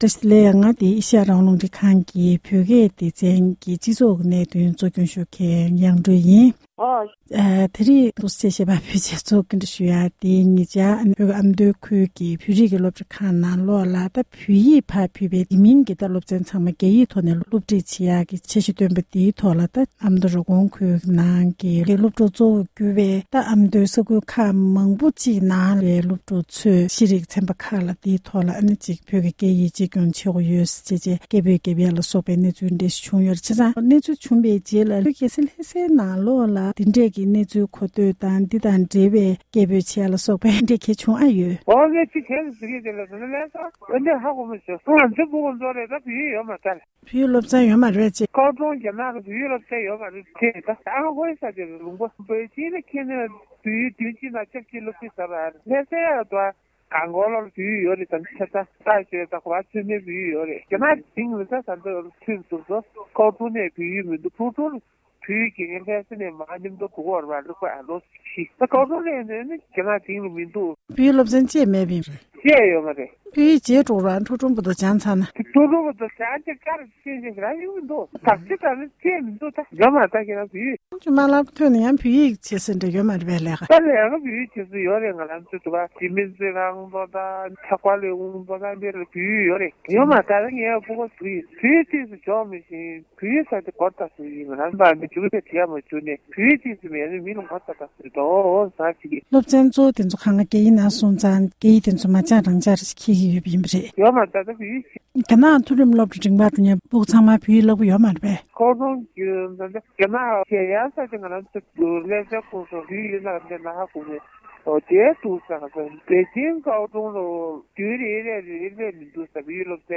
ལྷ་སའི་ནང་བཅའ་བཞུགས་འབྲེལ་ཡོད་མི་སྣར་གནས་འདྲི་ཞུས་པར་གསན་རོགས༎